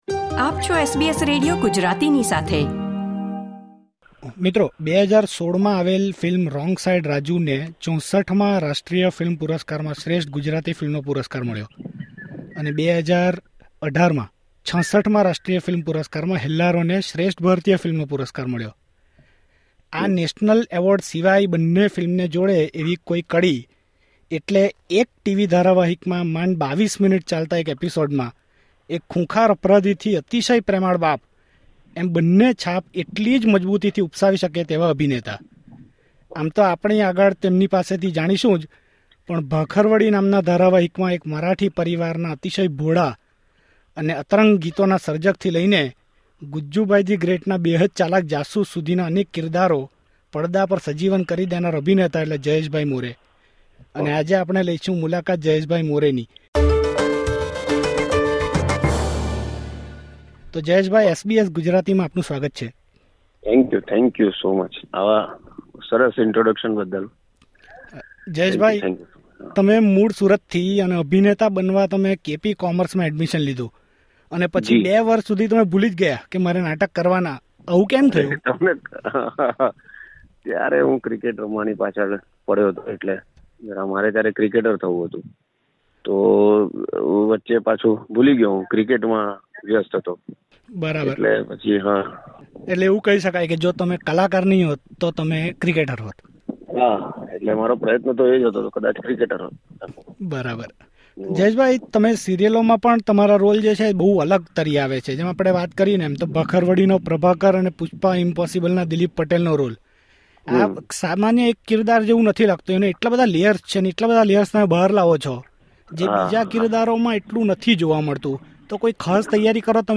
ફિલ્મના પ્રીમિયર પ્રસંગે ઓસ્ટ્રેલિયાની મુલાકાતે આવ્યા હતા પ્રખ્યાત અભિનેતા જયેશ મોરે. જેમણે તેમની ફિલ્મ યાત્રા વિષે અને ગુજરાતી સિનેમા વિશે SBS Gujarati સાથે રોચક વાતો કરી હતી.